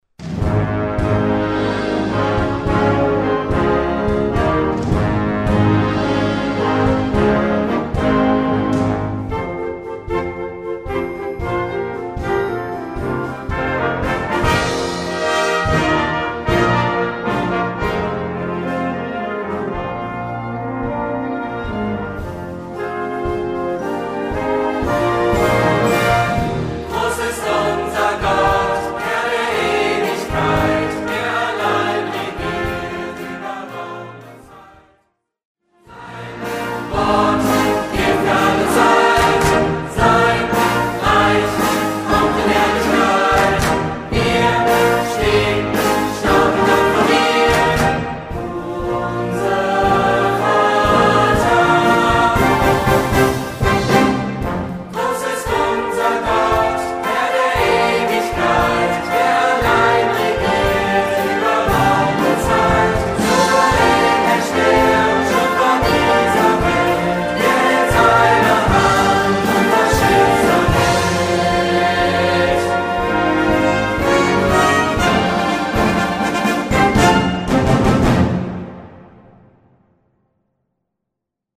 Gattung: Kirchenwerk mit Gesang (ad lib.)
Besetzung: Blasorchester